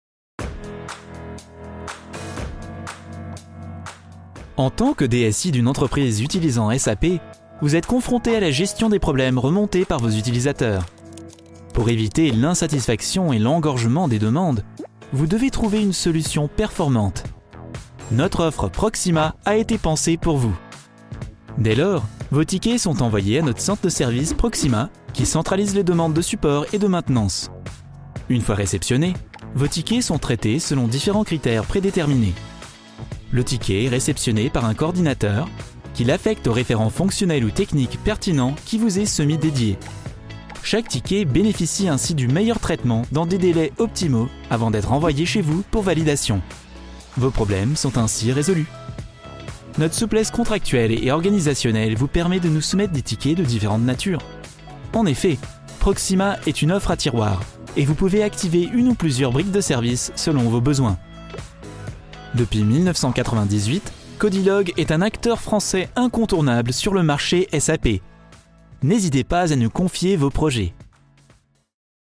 Junge, Natürlich, Warm, Sanft, Corporate
Erklärvideo